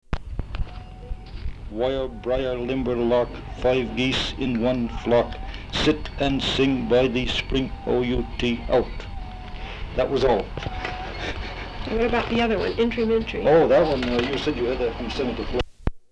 folksongs
Folk songs, English--Vermont
sound tape reel (analog)